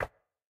step_concrete.ogg